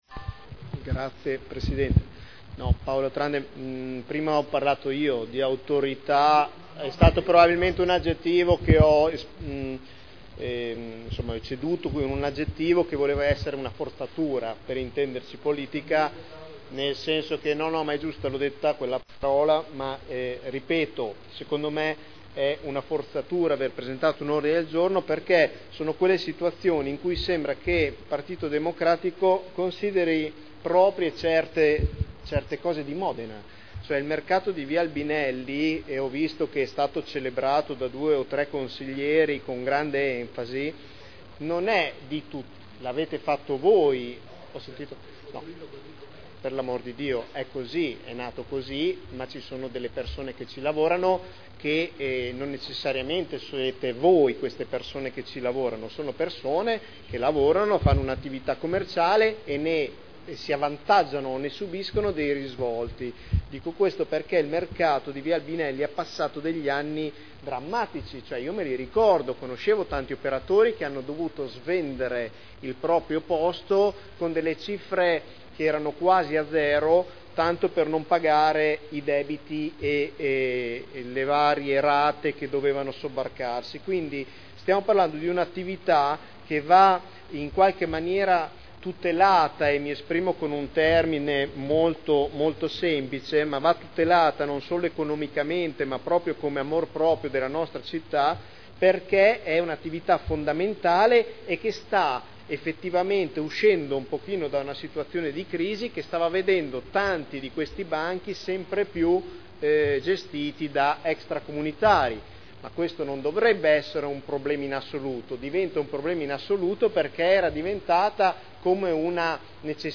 Nicola Rossi — Sito Audio Consiglio Comunale
Regolamento comunale del mercato coperto quotidiano di generi alimentari denominato “Mercato Albinelli” – Approvazione modifiche Dichiarazione di voto